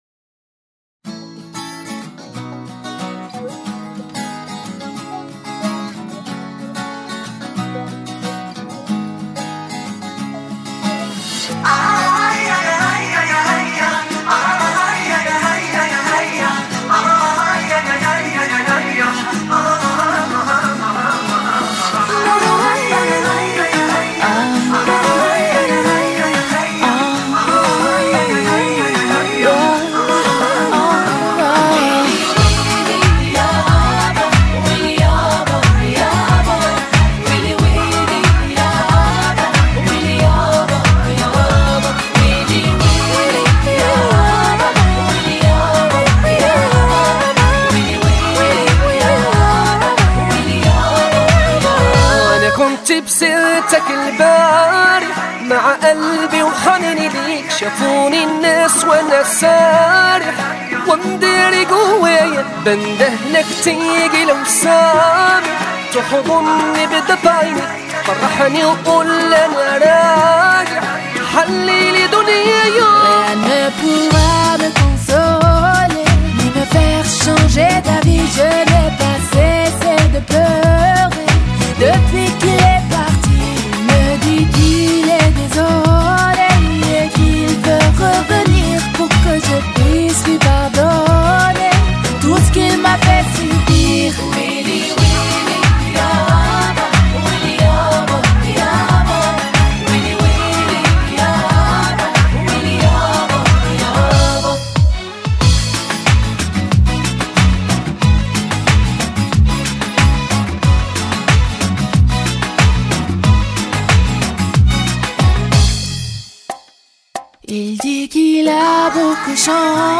他的唱法称为“Rai 籁乐”，是阿尔及利亚的传统唱法，奇妙的颤音极有穿透力。